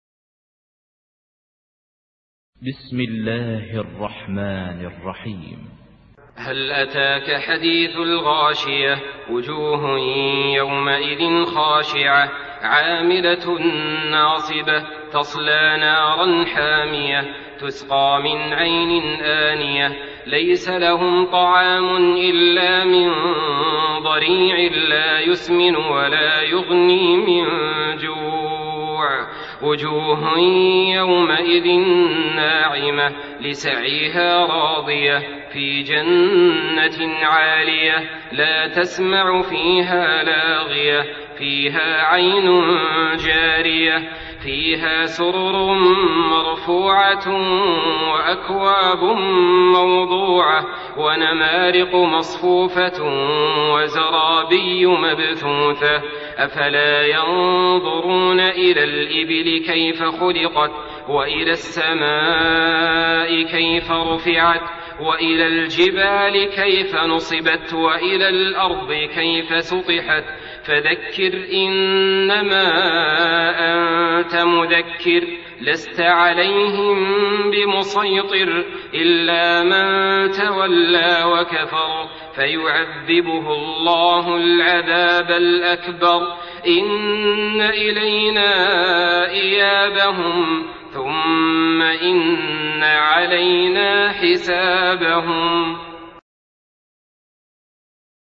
Surah আল-গাশিয়াহ্ MP3 by Saleh Al-Talib in Hafs An Asim narration.
Murattal Hafs An Asim